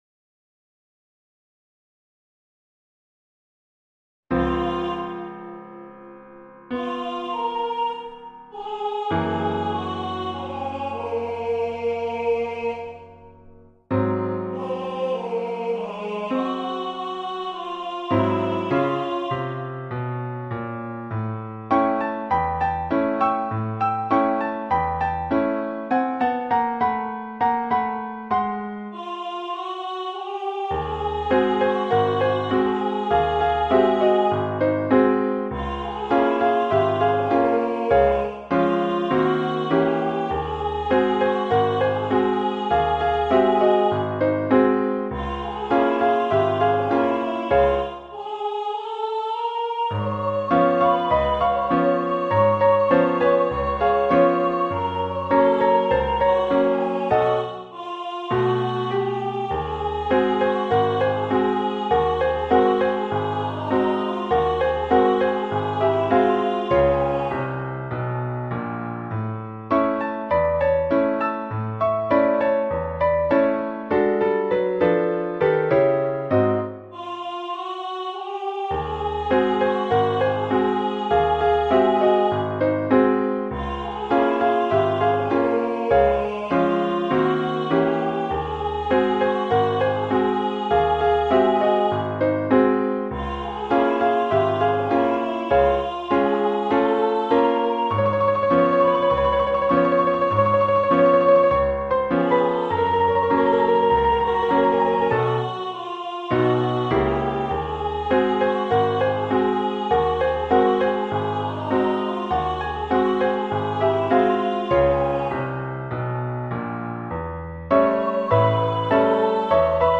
karaoke track made from the audio in musescore